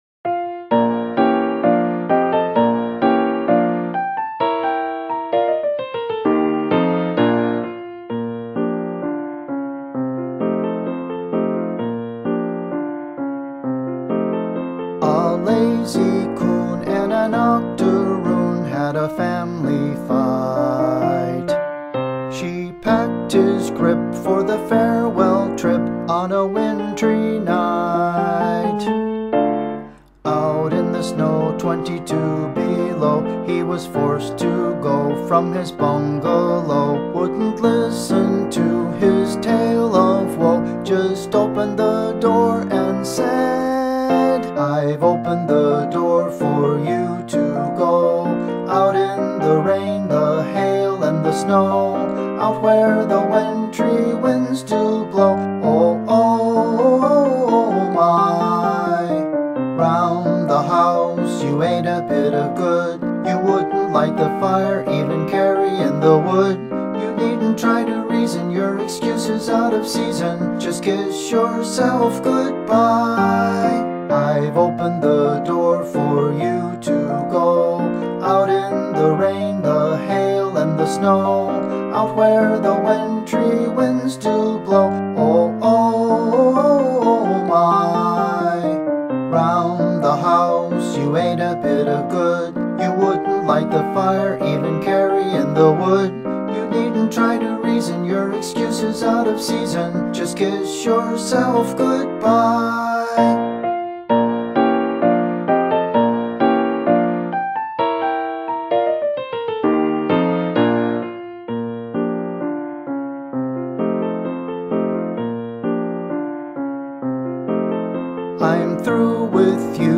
Midi Ragtime Recorder Ens.